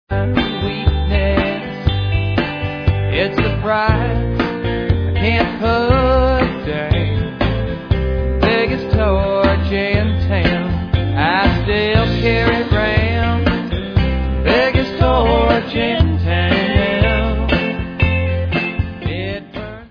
sledovat novinky v kategorii Country